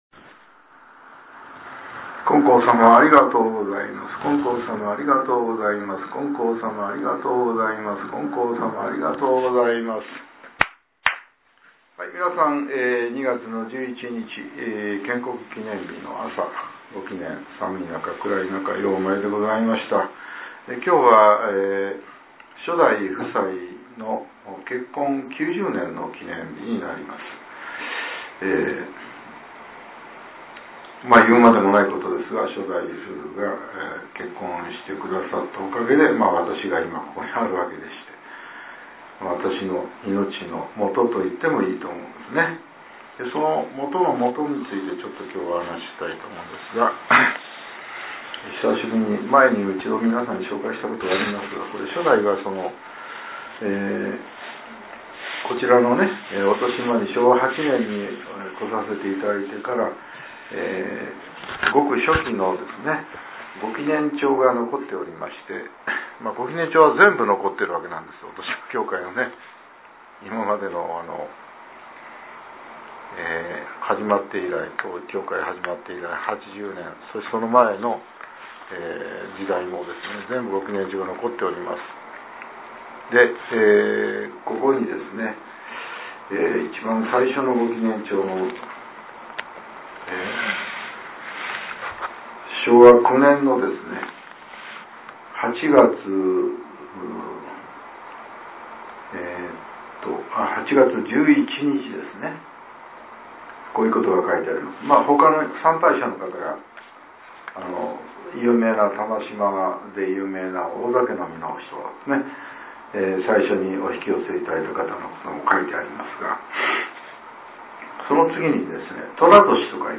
令和７年２月１１日（朝）のお話が、音声ブログとして更新されています。